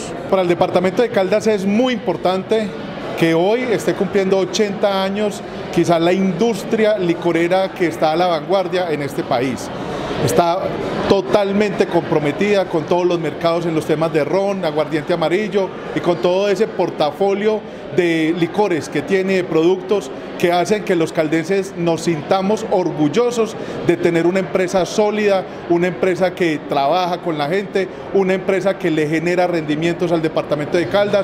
El pasado jueves se llevó a cabo en la Gobernación de Caldas un acto de conmemoración de las bodas de roble (80 años) de la empresa más importante del departamento.